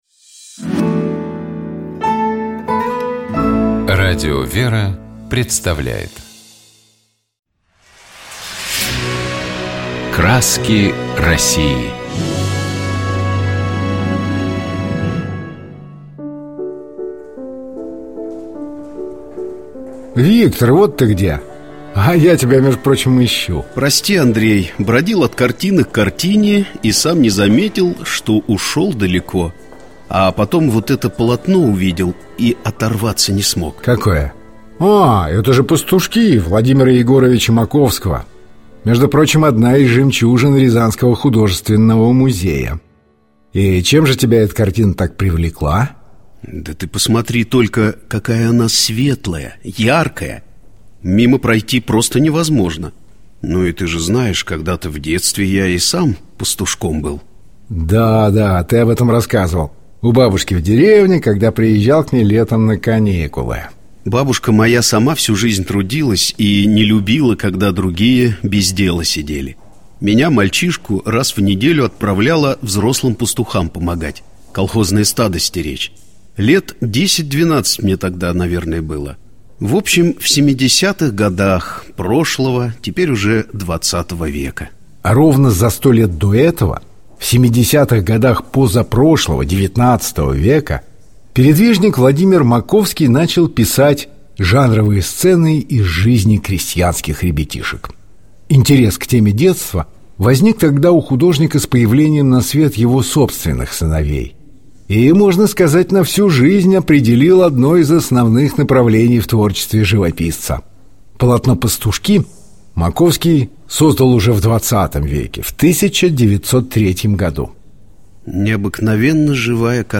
4 мая Святейший Патриарх Московский и всея Руси Кирилл совершил Божественную литургию в кафедральном соборе во имя святых Жён-мироносиц в городе Баку.